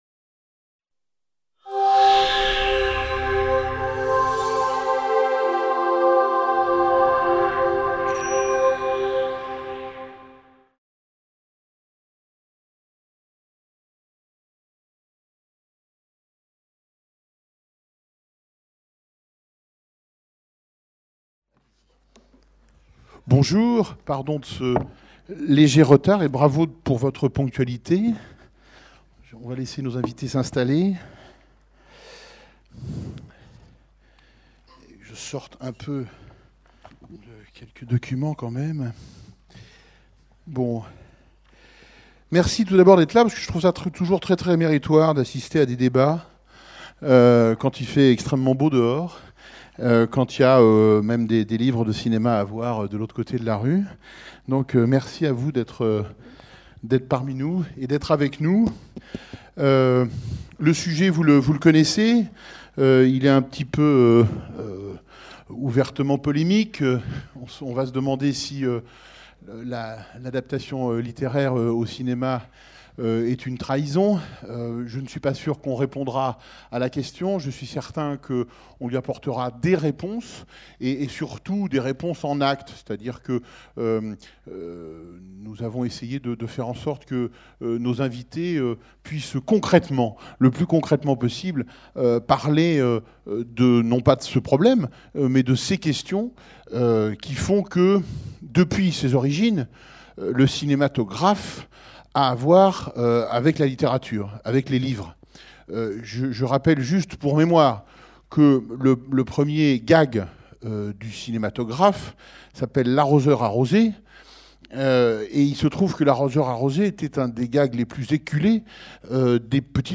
L'adaptation, une trahison ? Table ronde | Canal U